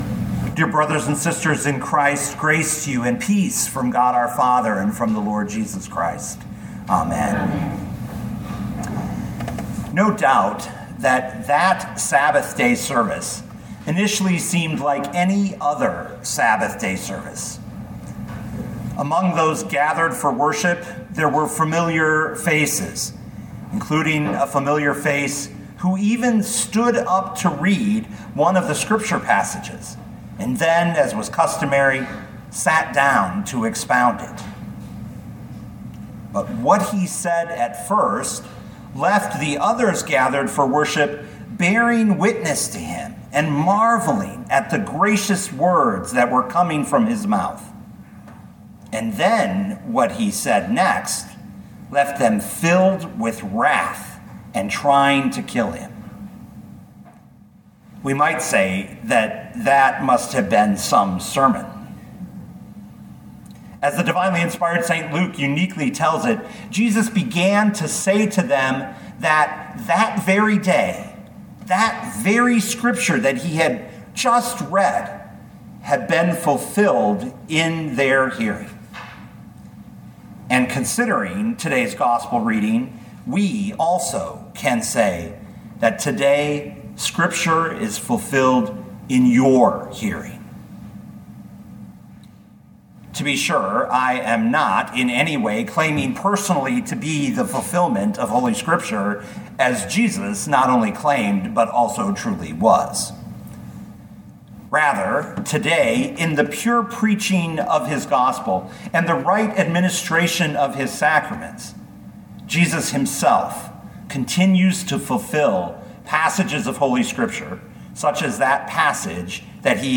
2022 Luke 4:16-30 Listen to the sermon with the player below, or, download the audio.